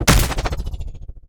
weapon_railgun_002.wav